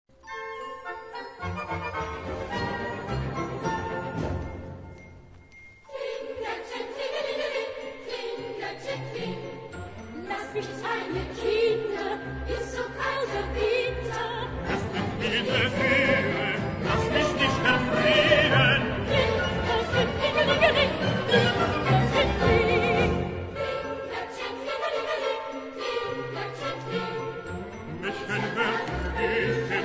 key: F-major
Sample Kling Gl�ckchen 9,00 EUR incl. 20% USt. zzgl.